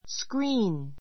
screen A2 skríːn スク リ ーン 名詞 ❶ （映画・テレビ・コンピューターなどの） スクリーン, 画面 ❷ ついたて, びょうぶ, （見えないように） 遮 さえぎ る物; （虫よけの） 網戸 あみど a window screen a window screen （窓にはめ込 こ む）網戸 ❸ 映画（界） The story was a success on stage and screen.